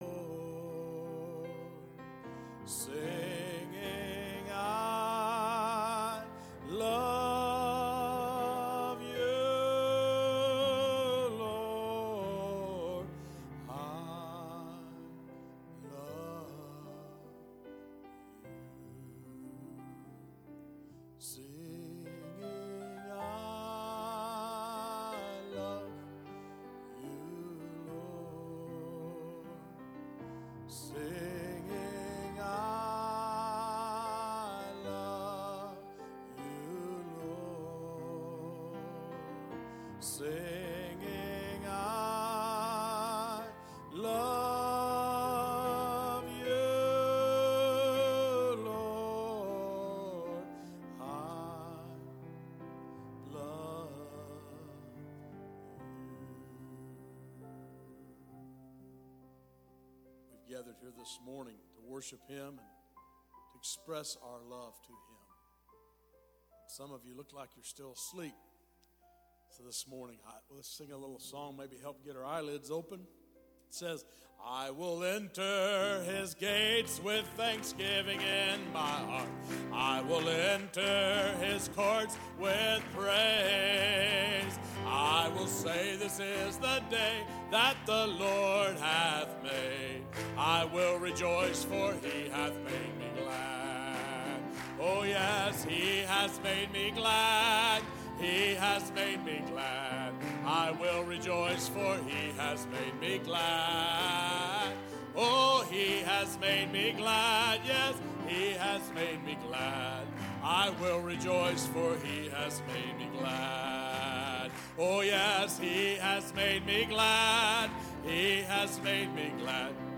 From Series: "2025 Sermons"